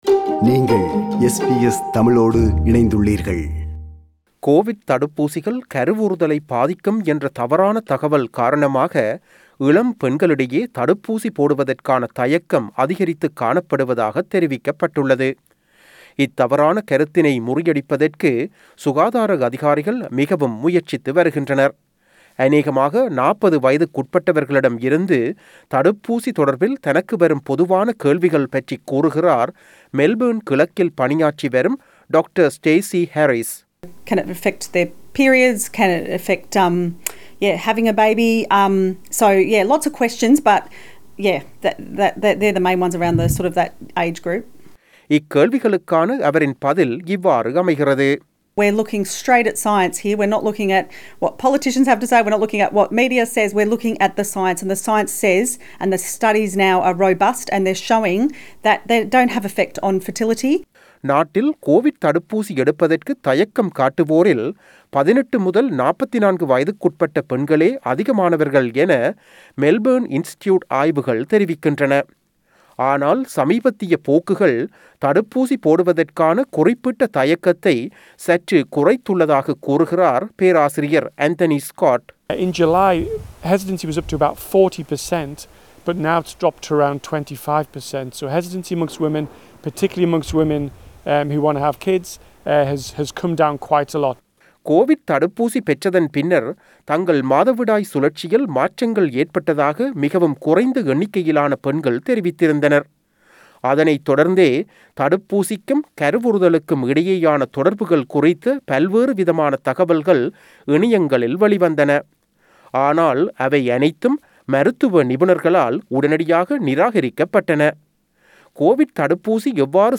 செய்தி விவரணத்தை